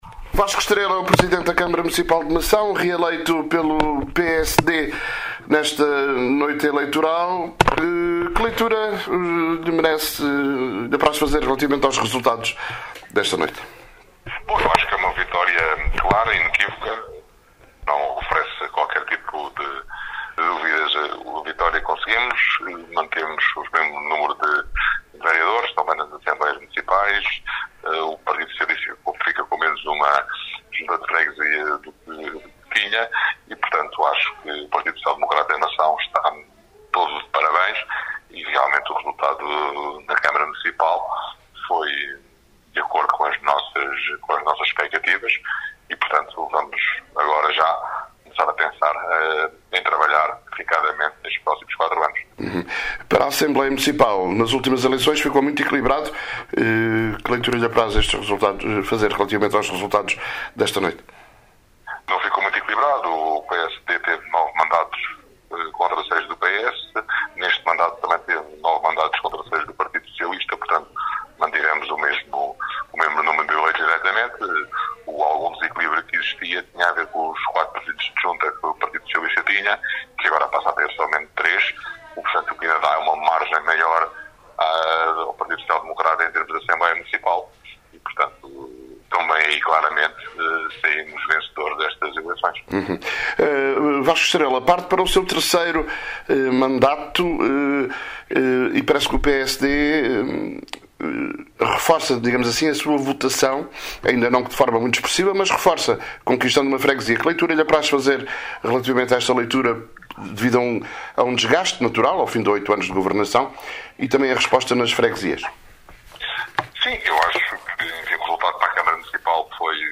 01:00 – Mação | Declarações de Vasco Estrela (PSD), reeleito presidente da Câmara de Mação com maioria absoluta (63% dos votos).